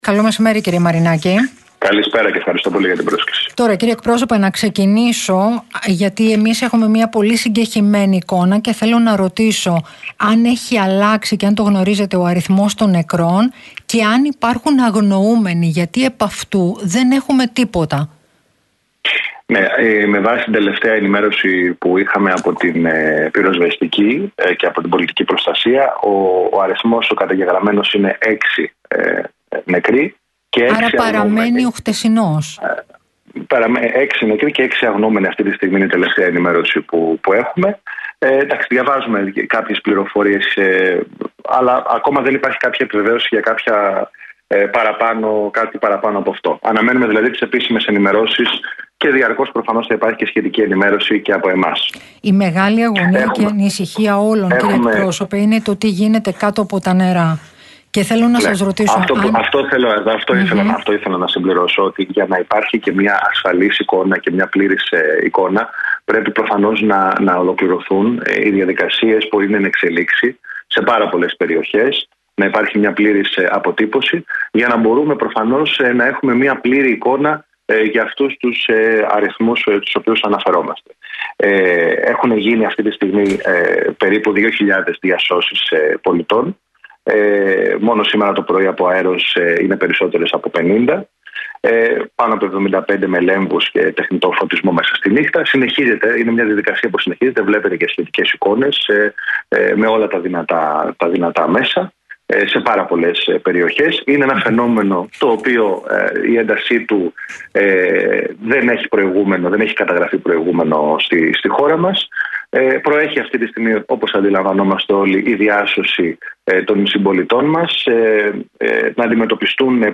Μαρινάκης στον Real fm 97,8: Έχουν γίνει έως τώρα 2.000 διασώσεις